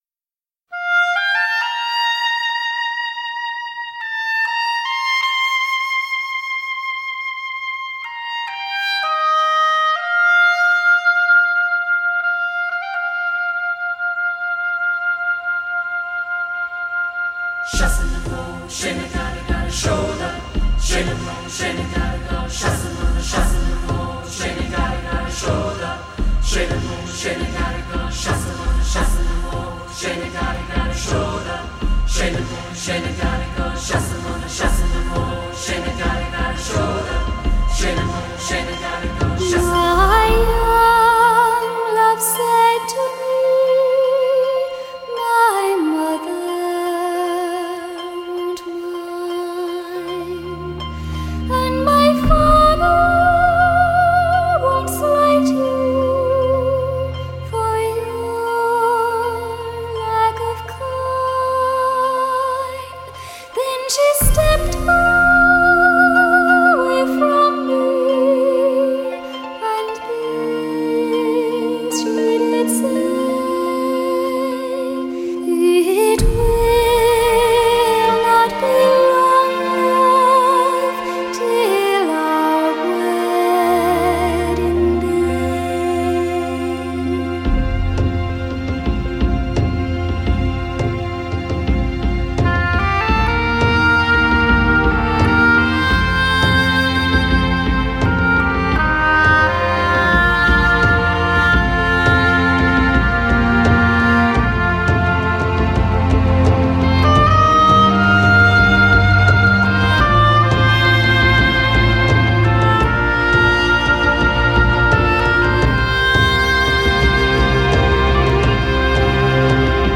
爱尔兰独特旋律·嗓音美妙·唱功无与伦比！
一个国际性乐团，以及有活力的爱尔兰传统乐器演奏家的演奏和令人惊心动魄的爱尔兰舞曲，